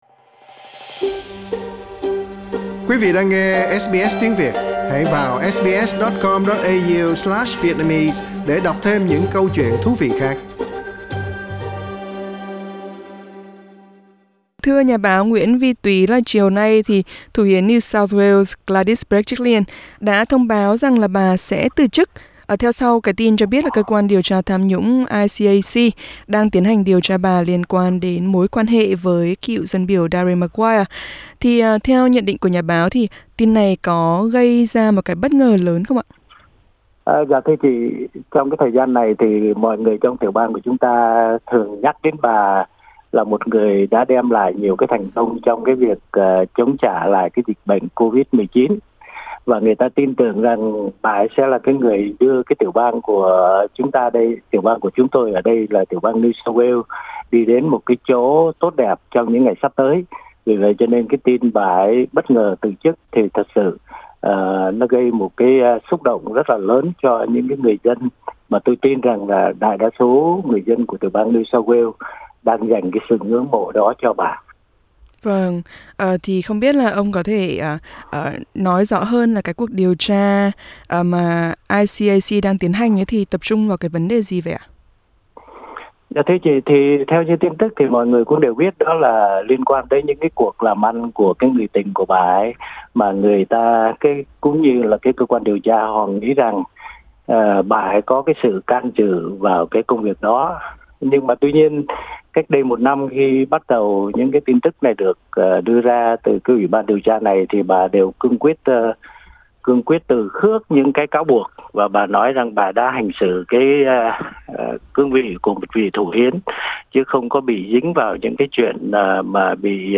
Xin mời quý vị bấm vào hình để nghe toàn bộ nội dung cuộc trò chuyện.